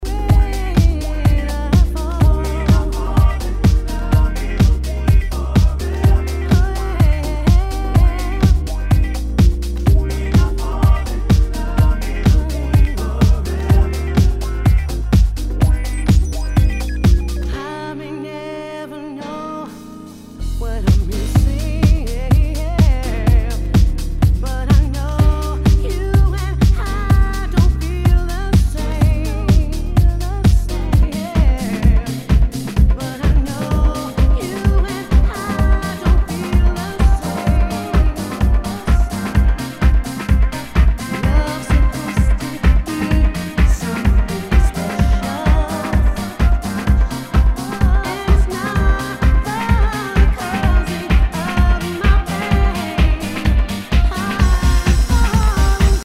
HOUSE/TECHNO/ELECTRO
ナイス！ディープ・ヴォーカル・ハウス！